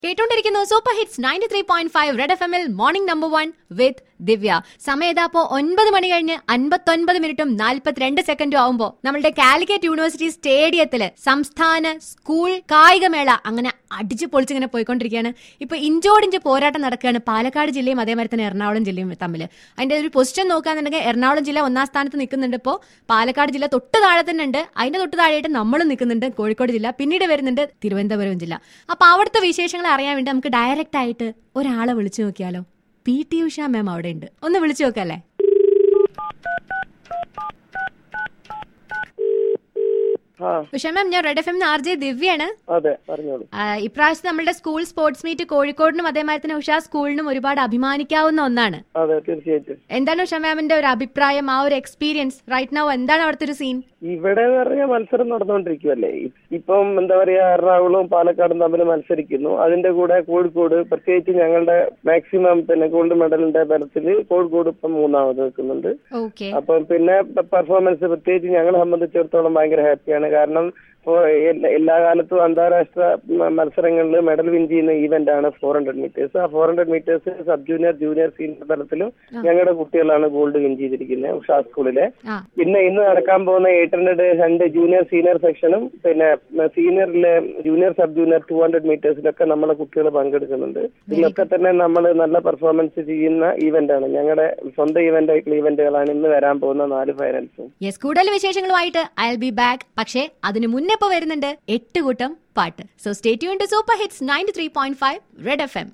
P.T. USHA CHAT REGARDING STATE SCHOOL SPORTS MEET.